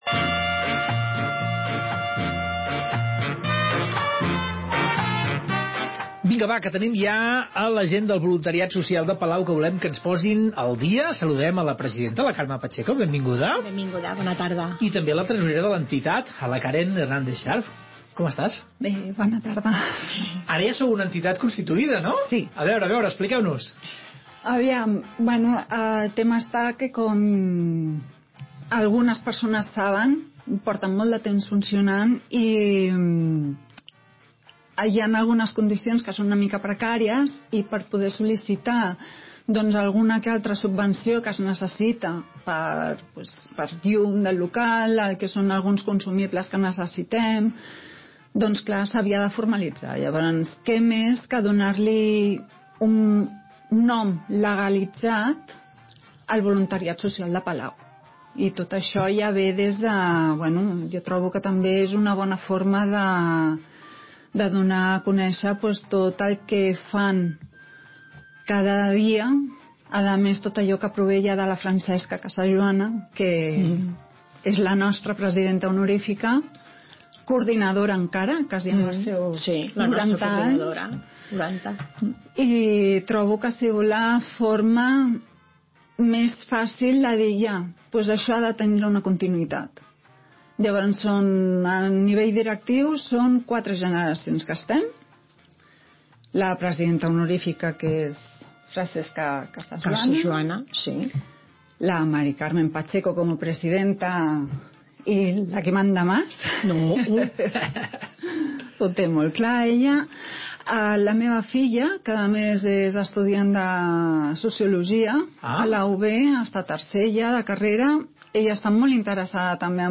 Entrevista
por Escolta la nostra entrevistes a Ràdio Palau